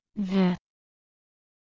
子音/ð/は、舌と歯の間で空気が摩擦することで発音できる「歯摩擦音(は まさつおん)」という音です。
子音/ð/のみの発音
子音有声音thのみの発音.mp3